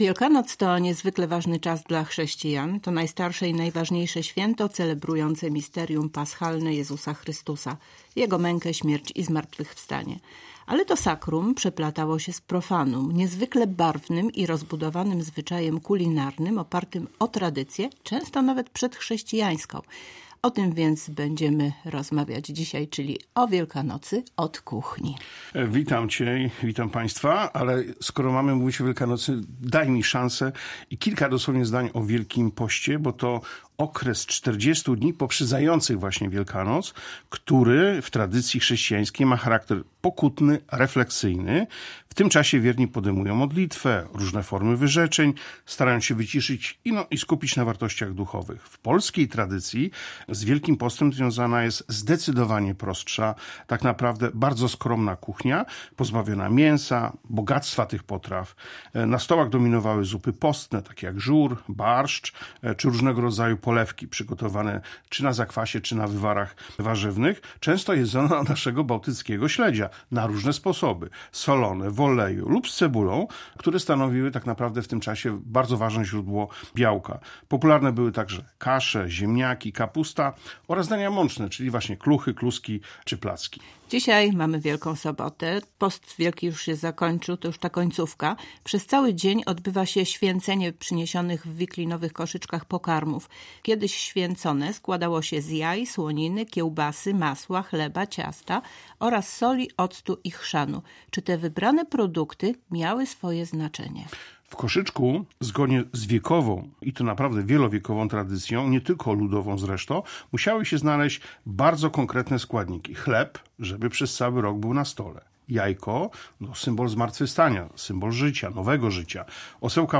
Więcej o świątecznych tradycjach w rozmowie